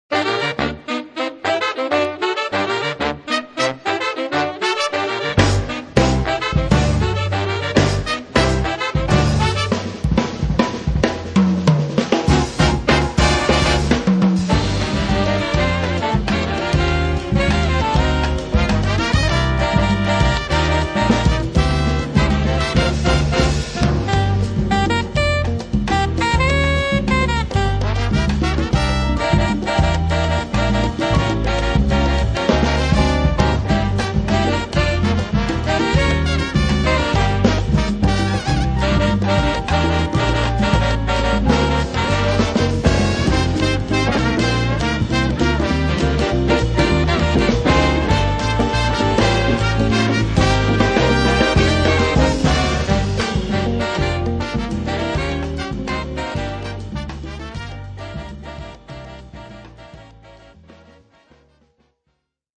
Venature latineggianti